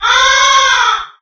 Crow.ogg